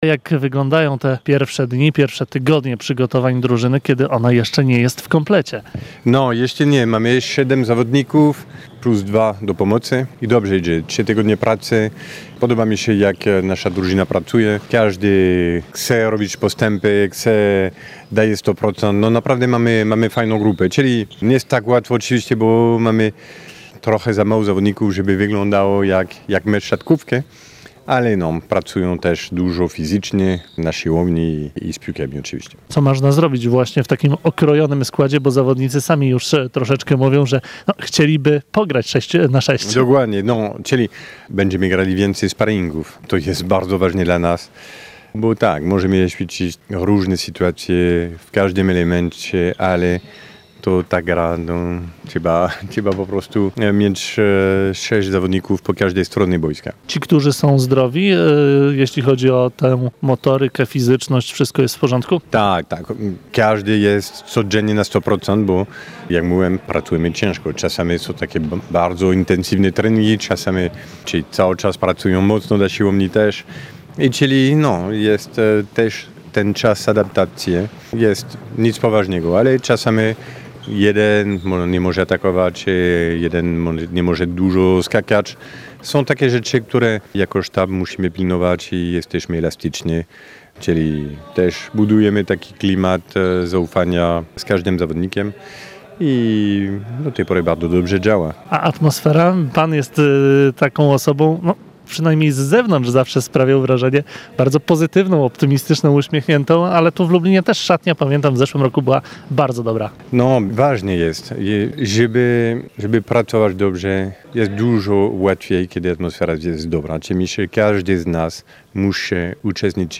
Stephane-Antiga-rozmowa.mp3